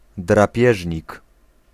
Ääntäminen
IPA: /kɑrniˈvoːr/